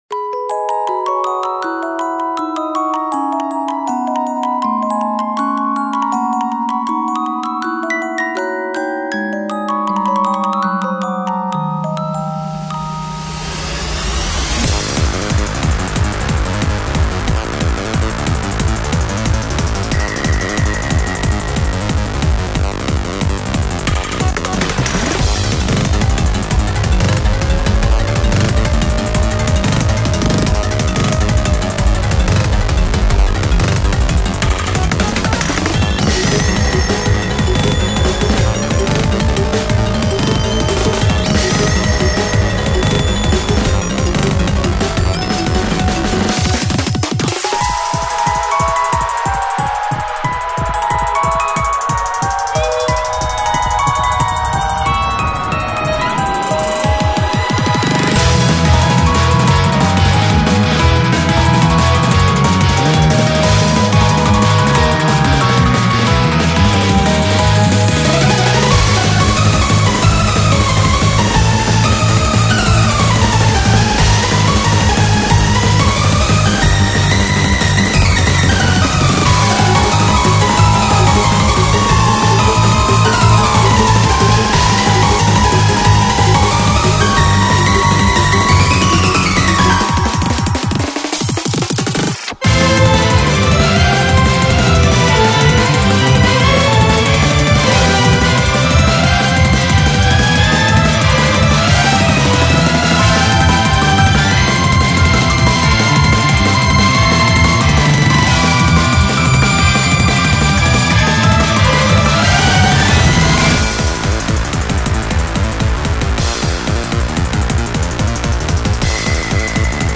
ジャンルゴシック、EDM
BPM１６０→１８２
使用楽器ピアノ、ヴァイオリン、シンセリード、オルゴール
解説スピード感のあるサイバーな戦闘曲フリーBGMです。
近未来バトルの世界観にゴシックのエッセンスを追加して制作いたしました。
トリッキーな構成となっておりますので、音ゲーやラスボス戦、配信BGMなどにピッタリです。